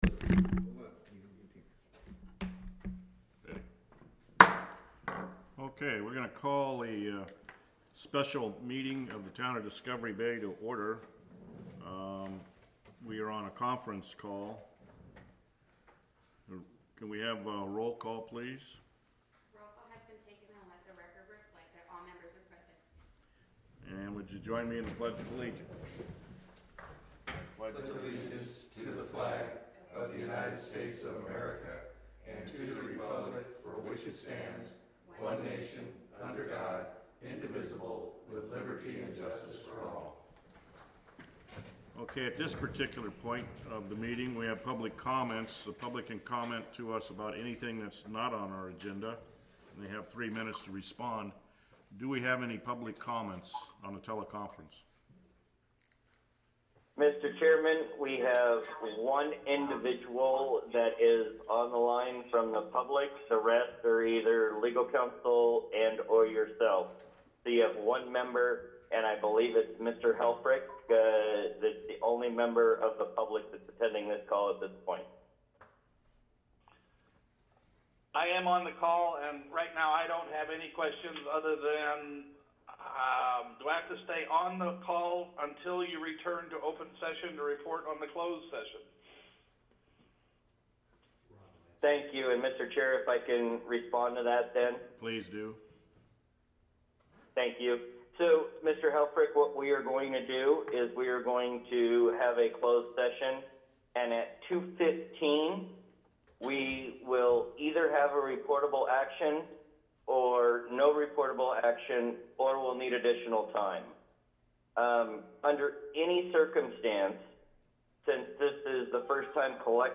The Town of Discovery Bay CSD meets twice monthly on the first and third Wednesday of each month at 7:00 p.m. at the Community Center located at 1601…
Board of Directors Meeting